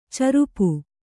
♪ carupu